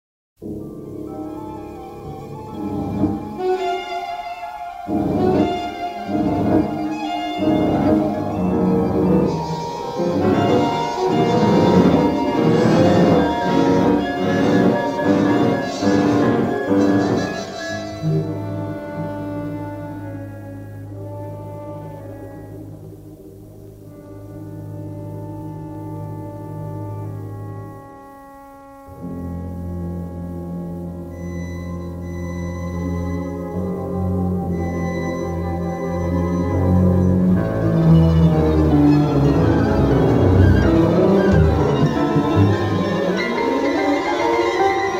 the balance of the CD is in mono.